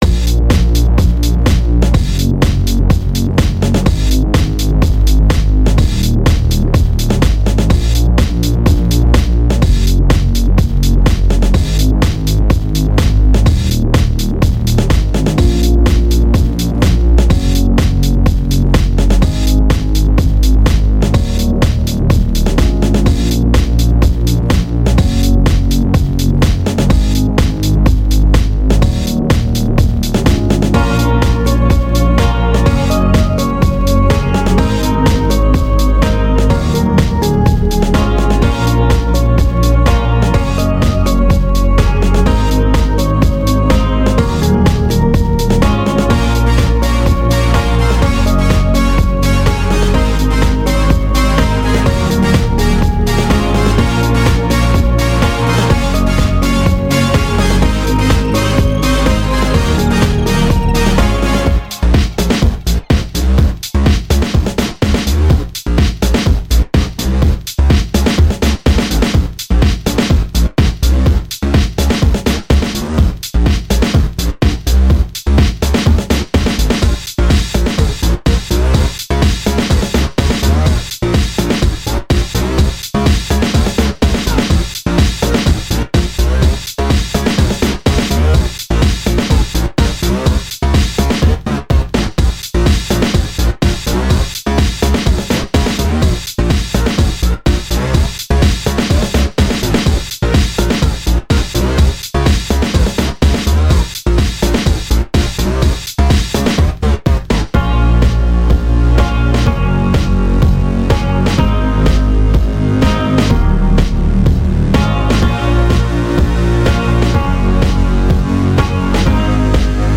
This is a piece I wrote a few months ago in GarageBand, and I thought that I should share it with everyone!
dubstep
synth